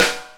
Twisting 2Nite Snr Rimshot.wav